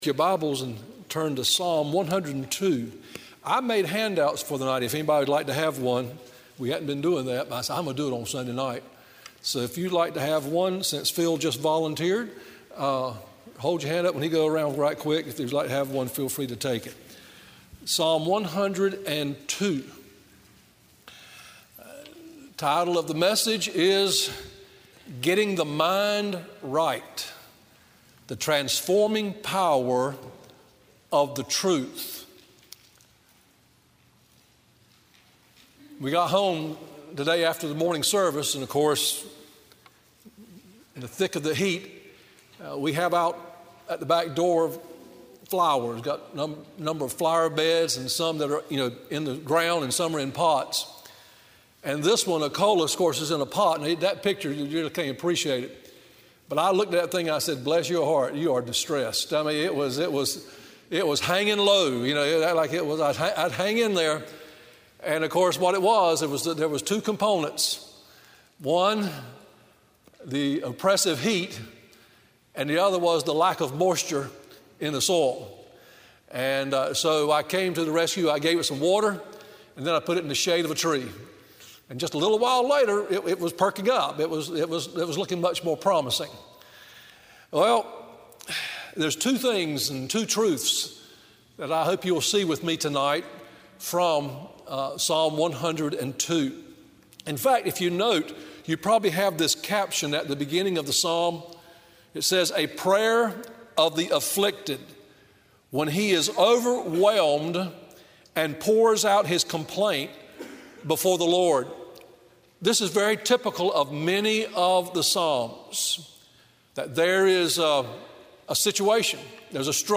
Sermon Audios/Videos - Tar Landing Baptist Church